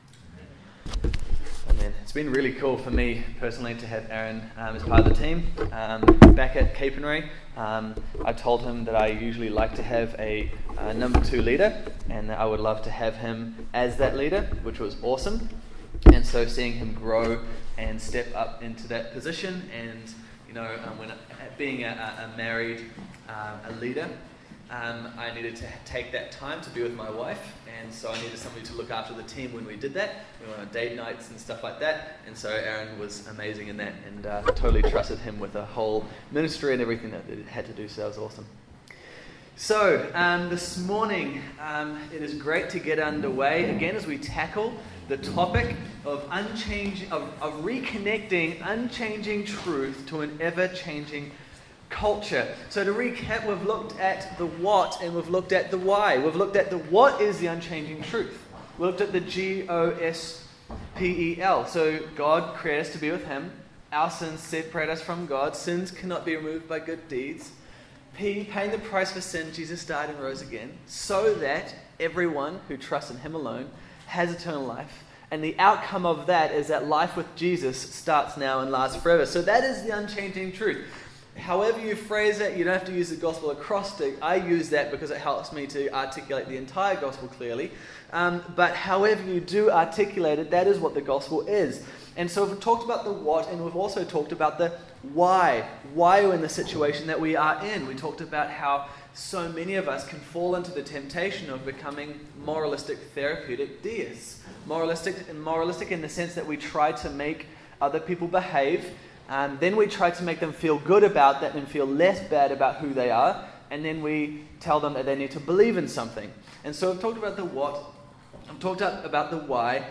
Church Camp Session 3 | Reconnecting Unchanging Truth to an Ever Changing Culture